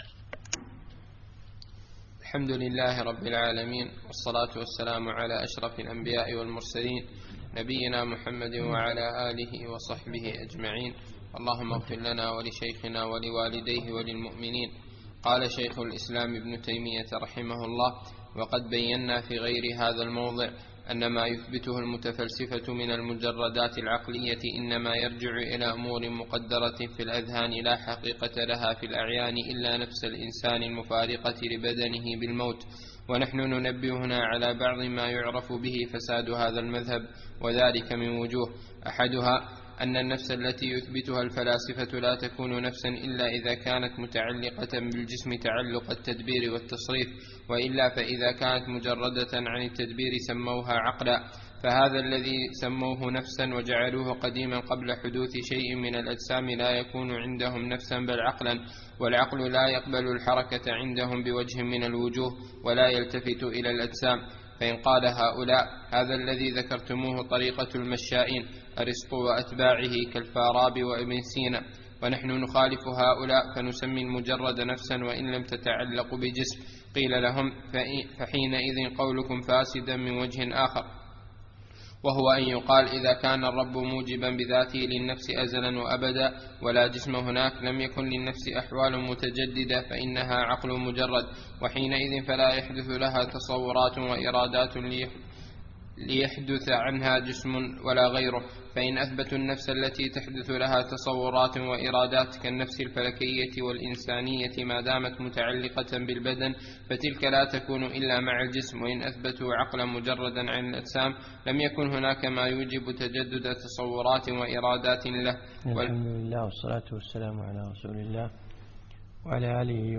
من دروس الشيخ في دولة الإمارات